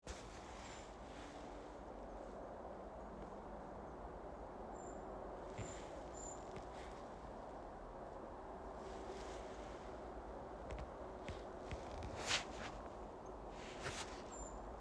Birds -> Treecreepers ->
Common Treecreeper, Certhia familiaris